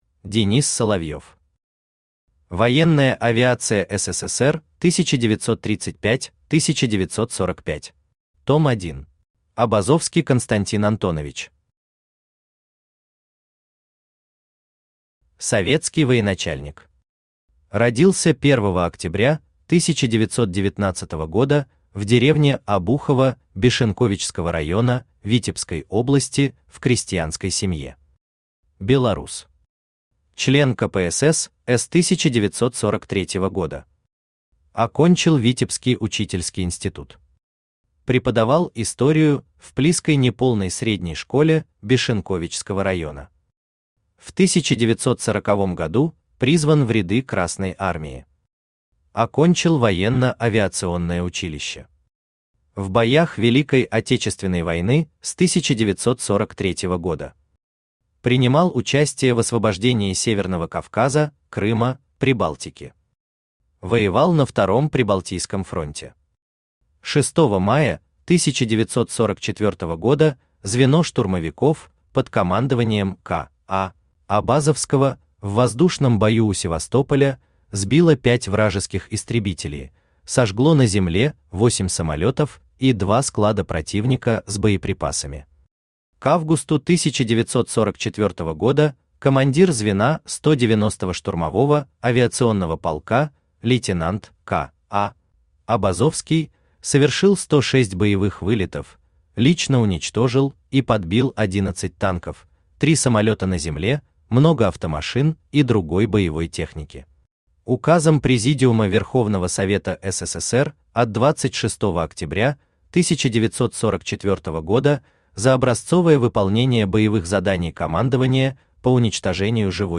Том 1 Автор Денис Соловьев Читает аудиокнигу Авточтец ЛитРес.